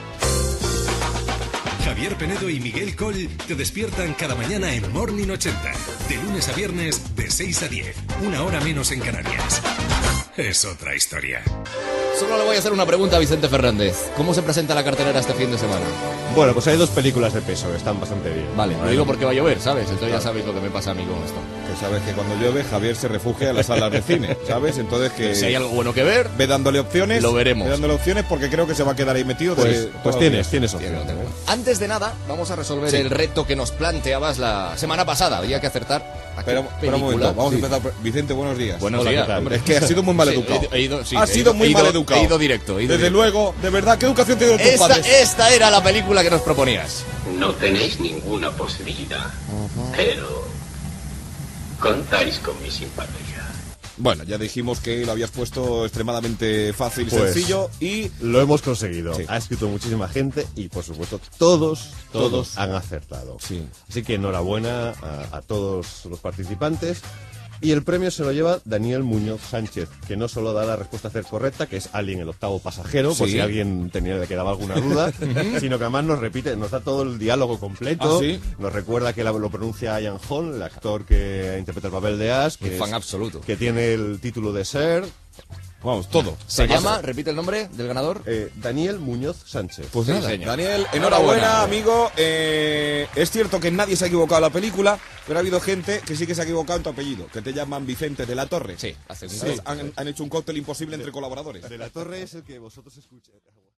Indicatiu del programa, resolució del concurs de cinema i oïdor premiat
Entreteniment
FM